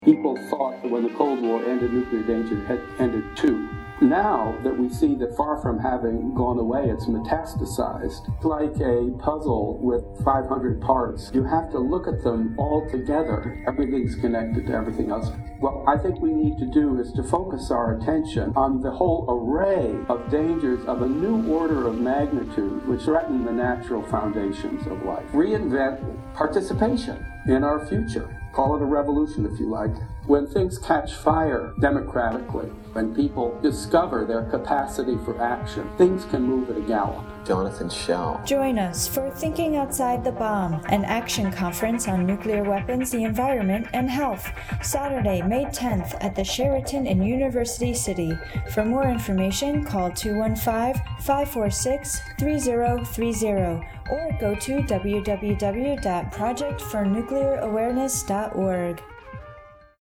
Through voiceovers, originally recorded sound and sampled music, the desired effect is acheived.
60sec_PNA_PSA_heartbeat.mp3